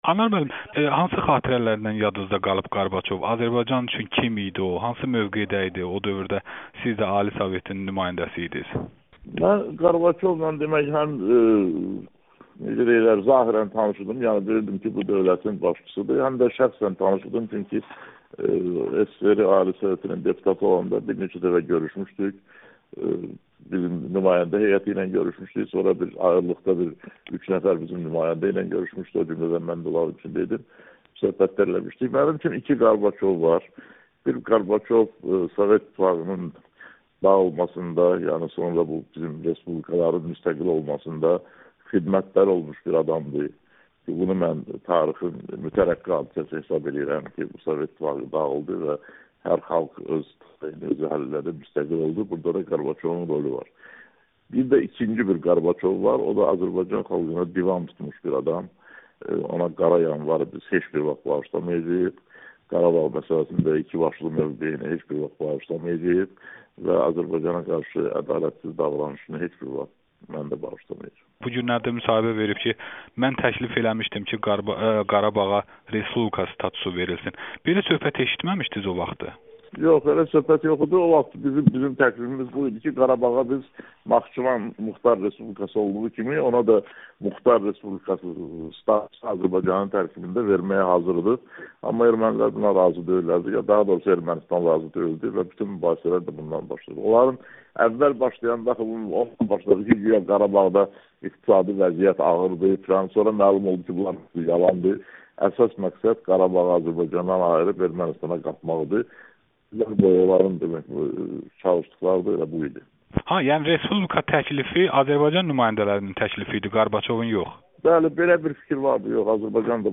Yazıçı Anarla qısa müsahibə